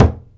BODHRAN 4A.WAV